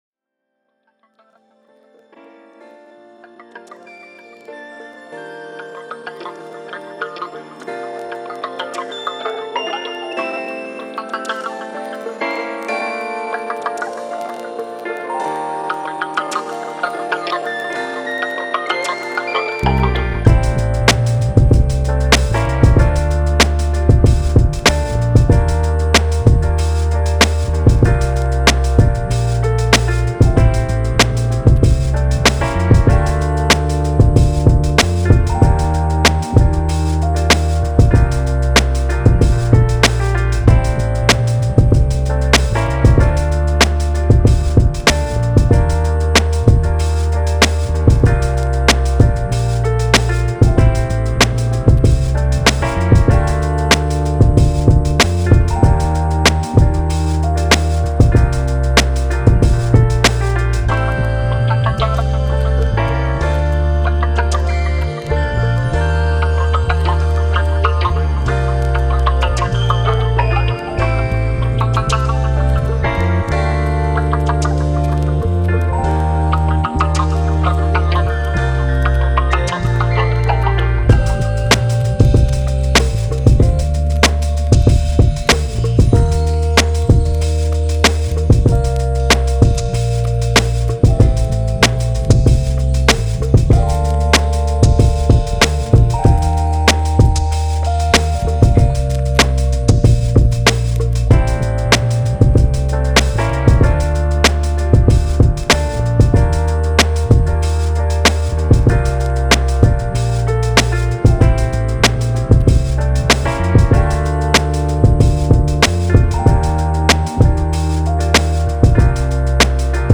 Hip Hop, R&B, Soul, 90s